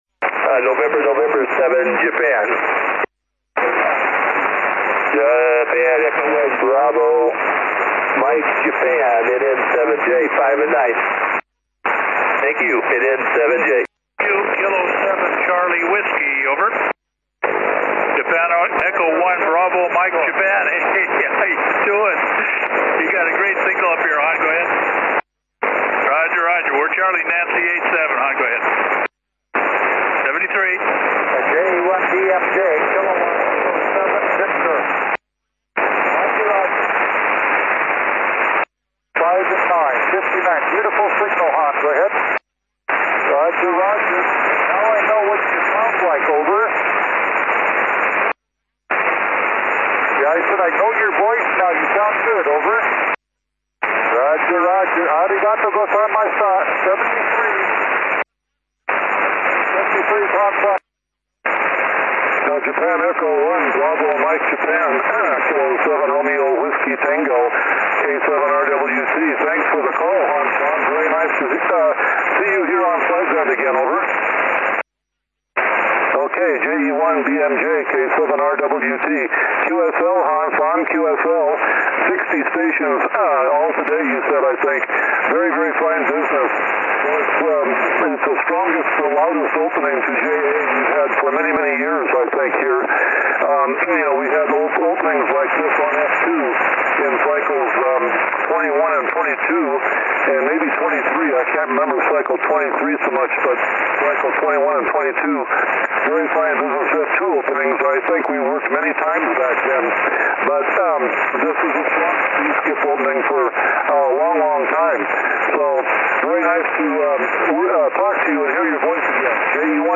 Signal were so strong like F2 opening at solar peak. As you can see on the DX-map there were strong piplelines between Japan and North America.